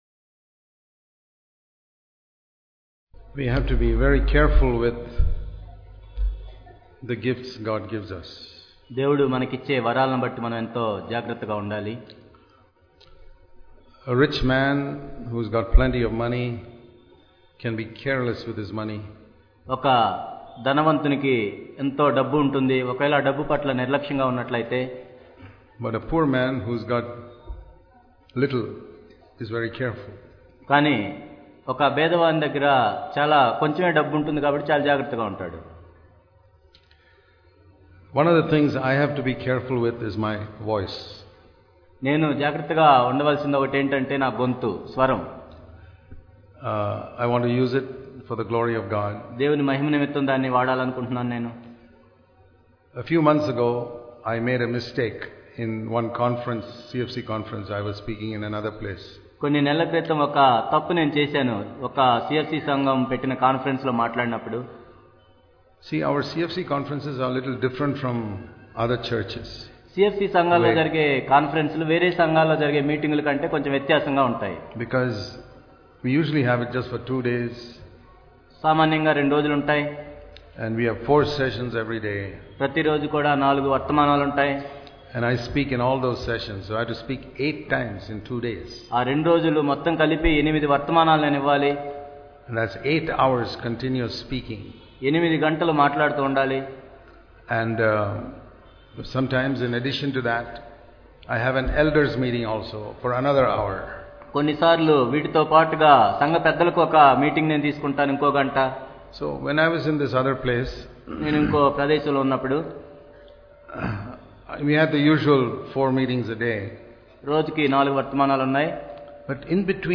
As Jesus is So are We in this World An Overcoming Life and True Fellowship Watch the Live Stream of the Hyderabad Conference 2015. Theme: An Overcoming Life and True Fellowship. 28th & 29th November 2015.